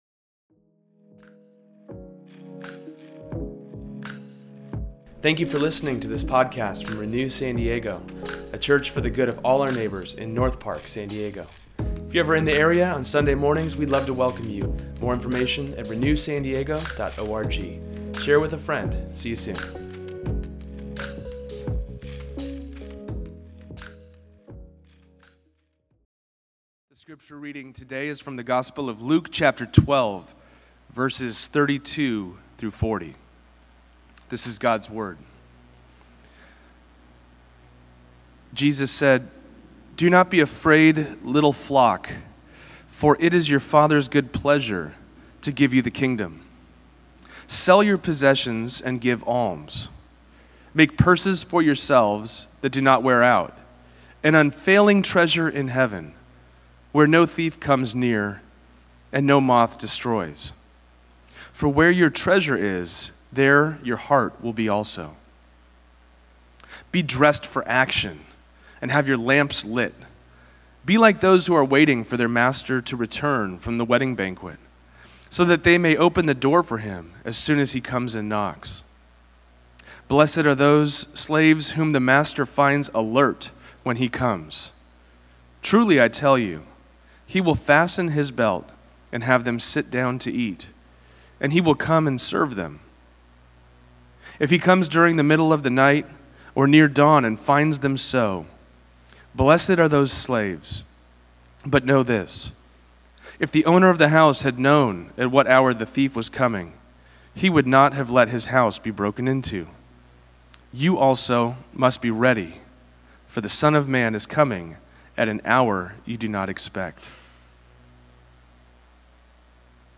Today’s sermon touches on KINGDOM readiness, kingdom READINESS, and KINGDON READINESS as a whole.